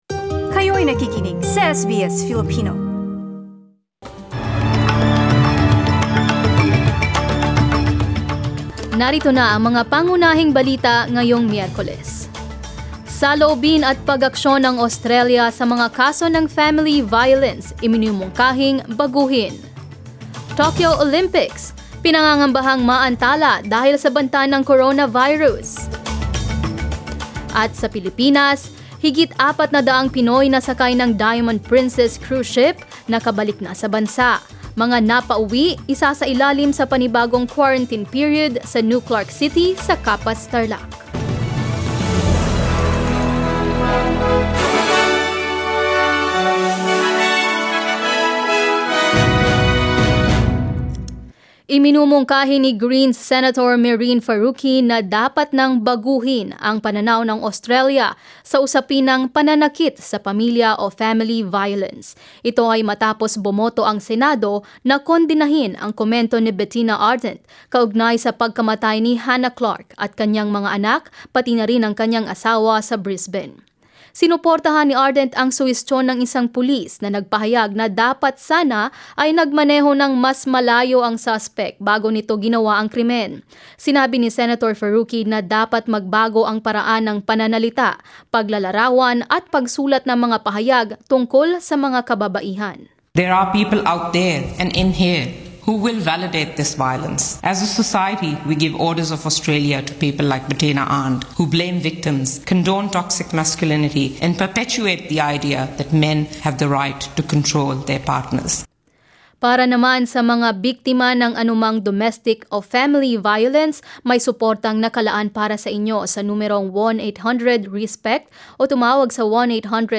SBS News in Filipino, Wednesday 26 February